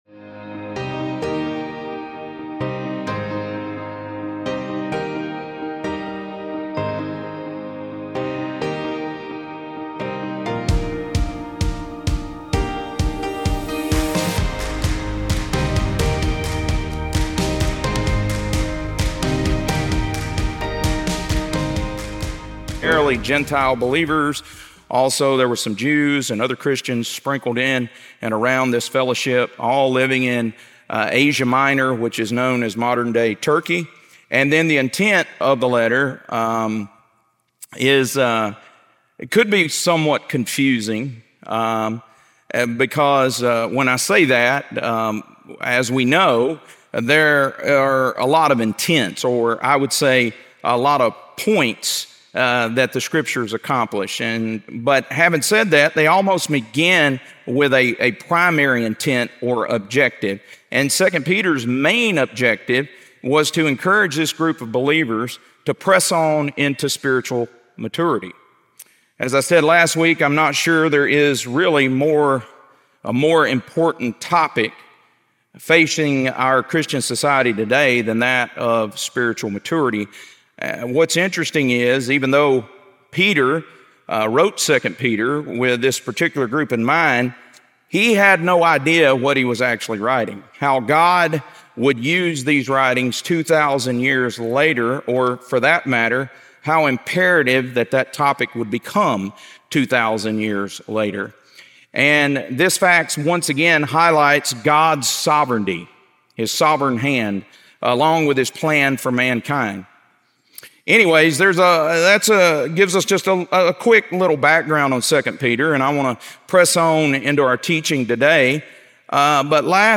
2 Peter - Lesson 1B | Verse By Verse Ministry International